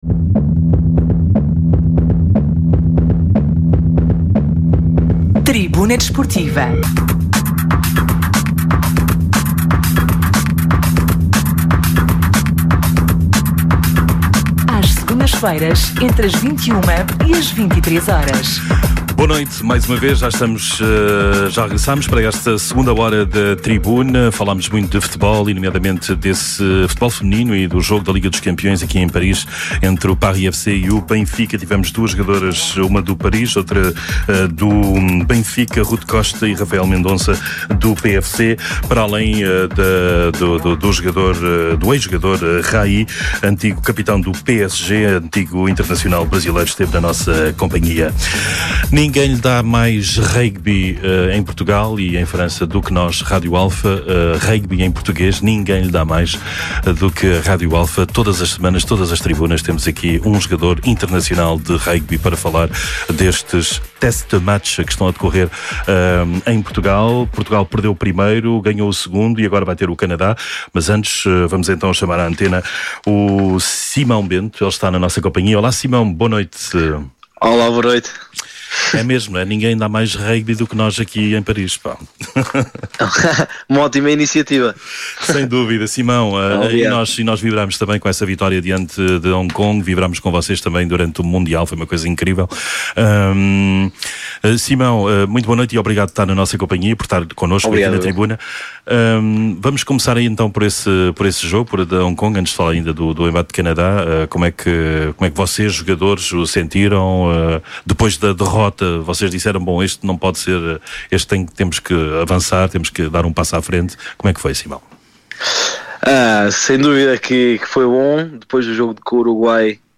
Atualidade Desportiva, Entrevistas.
Tribuna Desportiva é um programa desportivo da Rádio Alfa às Segundas-feiras, entre as 21h e as 23h.